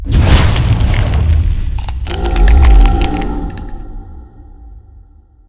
Mutate.ogg